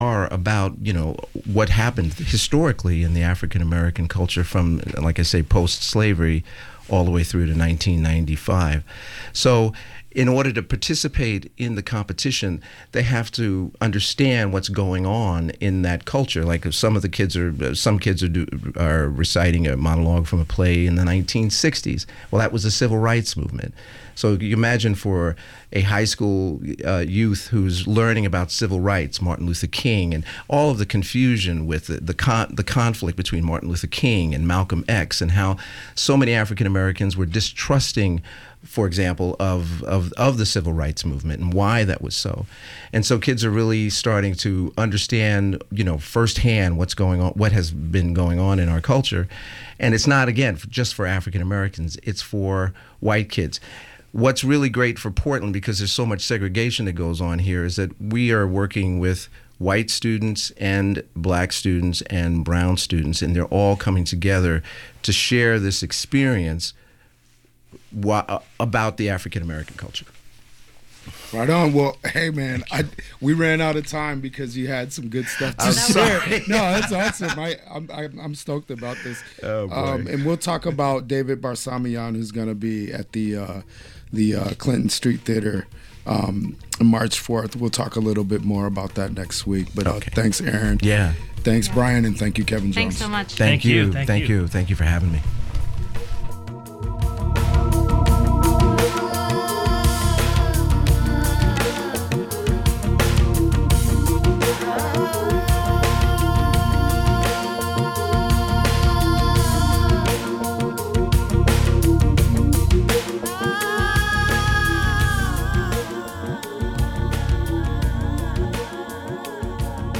Tonight we hear from speakers